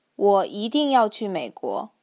Let’s investigate how well speech recognition does, provided that the input is standardised Mandarin and clearly enunciated by a native teacher.